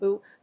/up(米国英語), u:p(英国英語)/